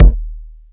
KICK.89.NEPT.wav